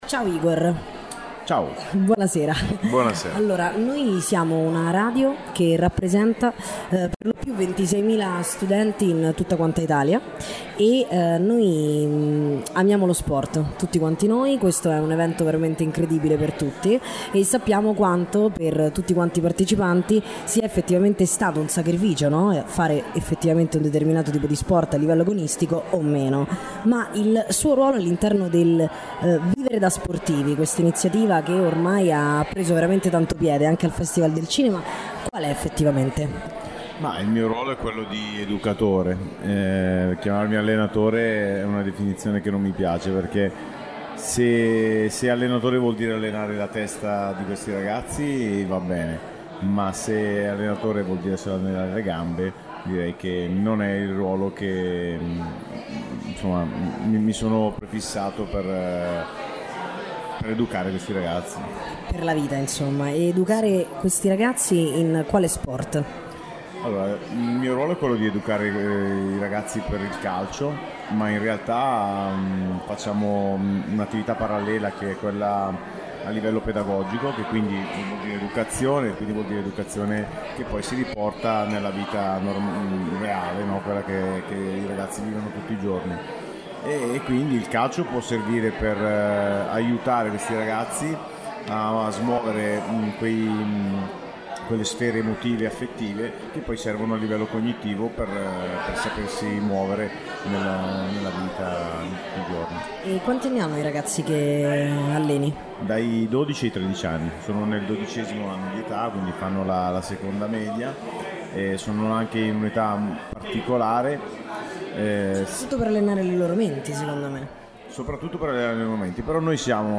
Intervista a